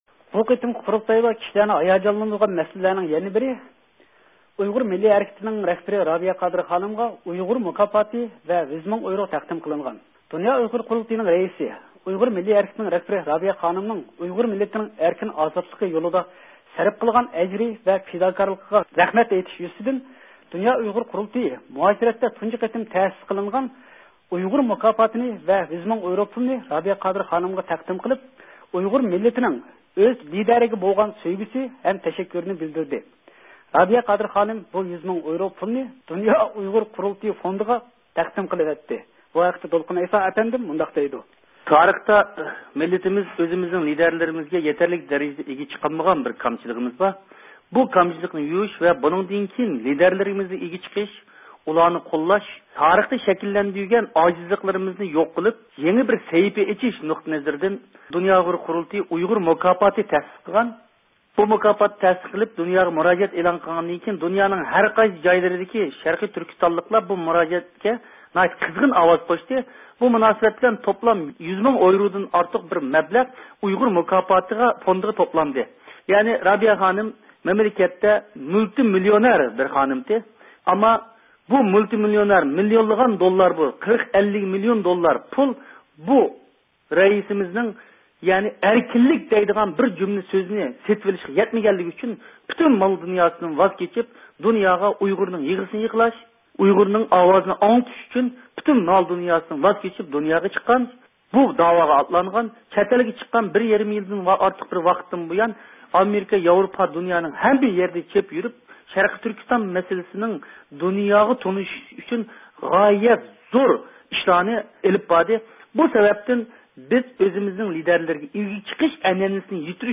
زىيارىتىنى قوبۇل قىلىپ